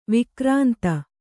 ♪ vikrānta